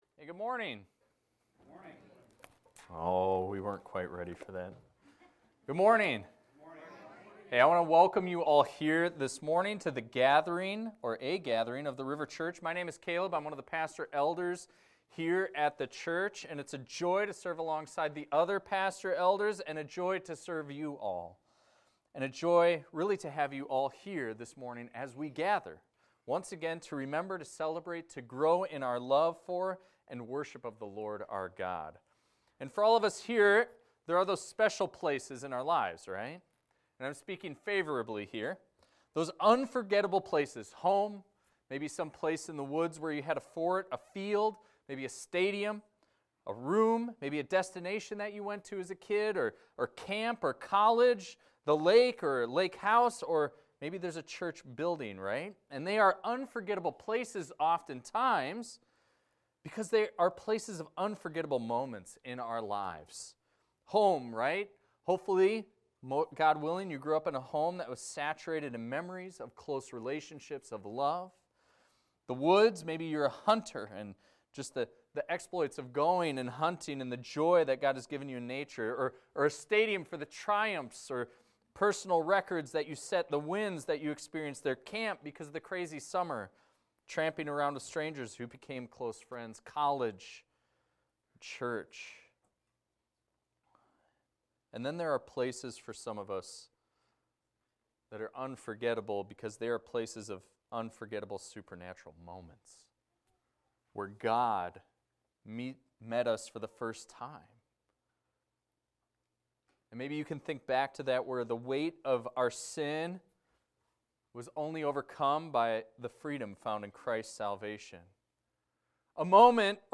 This is a recording of a sermon titled, "The Stairway to Heaven."